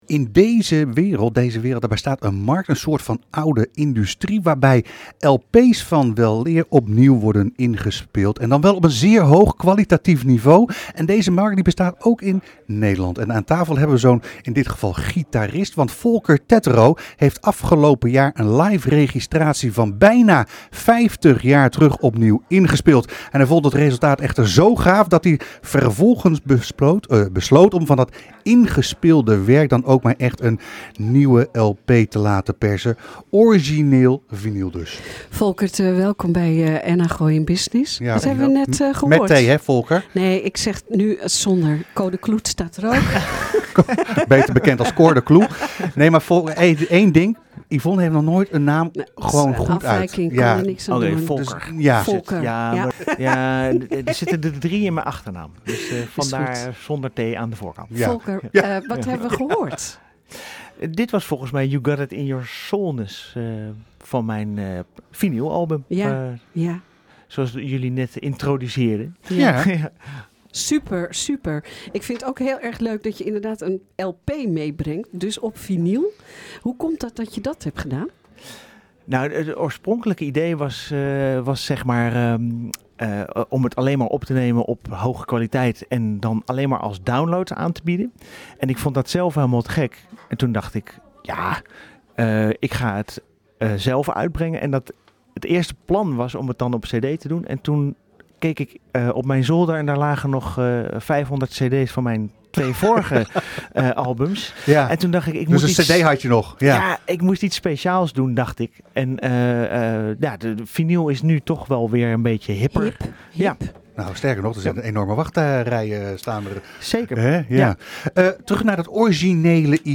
Er bestaat in deze wereld nog altijd een markt, neen een complete industrie, waarbij oude LP's van weleer opnieuw worden ingespeeld en dan op zeer hoog kwalitatief niveau anno 2020. Deze markt bestaat ook in Nederland. Aan tafel hebben we zo een in dit geval gitarist.